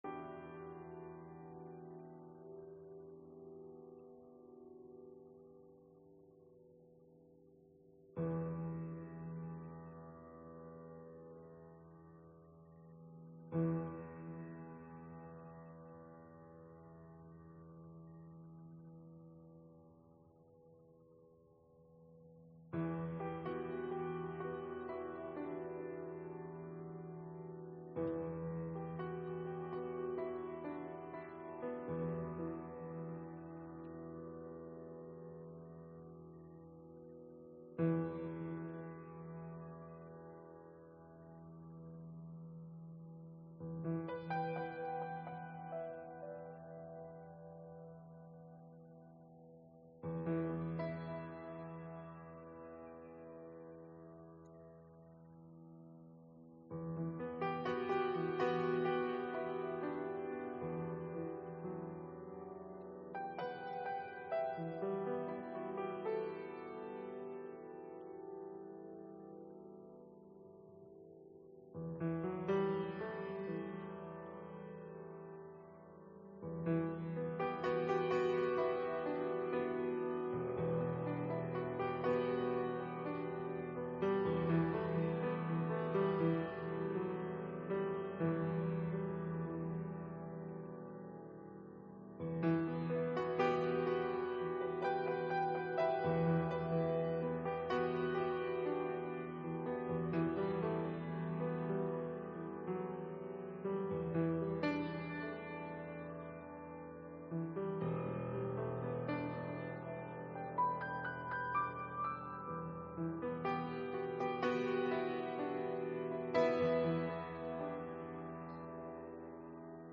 Soaking Prayer and Worship 9-2-25 audio only